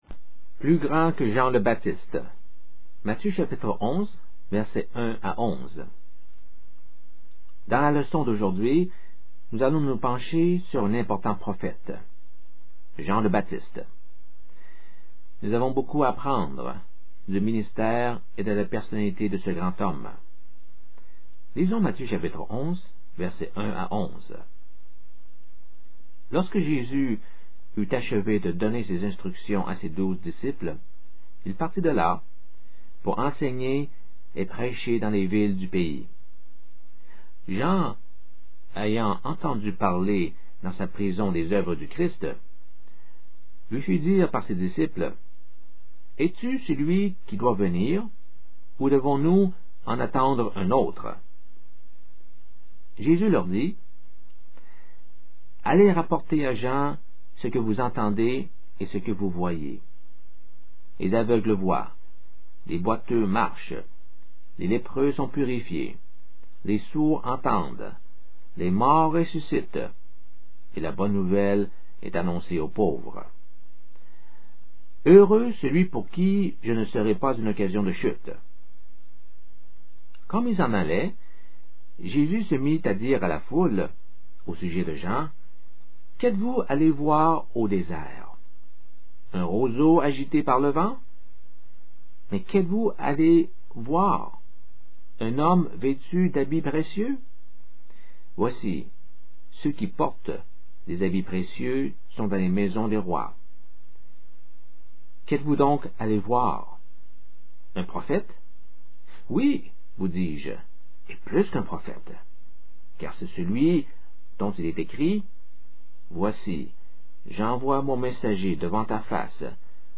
Dans la leçon d’aujourd’hui, nous allons nous pencher sur un important prophète : Jean le Baptiste. Nous avons beaucoup à apprendre du ministère et de la personnalité de ce grand homme.